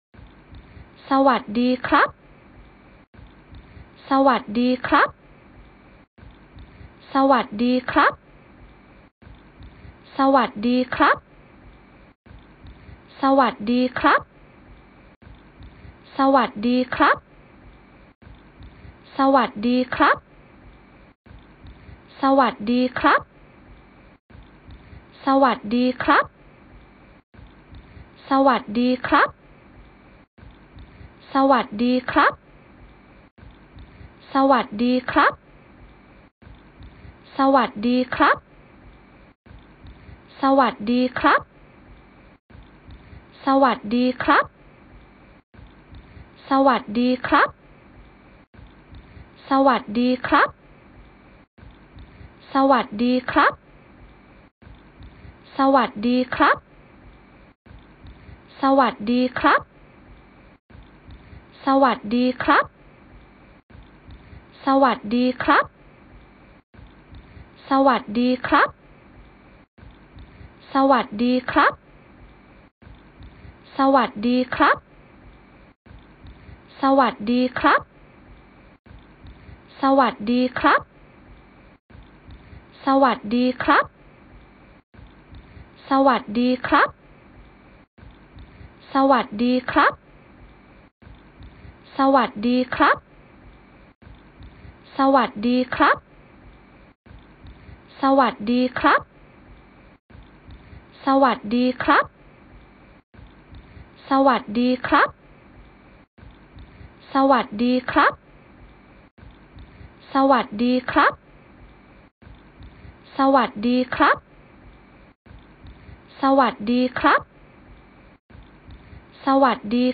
ฝึกนกพูด “สวัสดีครับ”
หมวดหมู่: เสียงนก
คำอธิบาย: นี่คือไฟล์เสียง ฝึกนกพูด สวัสดีครับ mp3 คุณภาพสูง โดยไม่มีเสียงรบกวน คุณสามารถใช้เปิดและฝึกให้นกพูดตามบันทึกนี้ได้ คุณสามารถใช้เสียงนกสวัสดีนี้เพื่อฝึกเสียงนก เช่น นกแก้วพูดได้, นกขุนทองพูดได้ และนกอื่น ๆ ที่พูดได้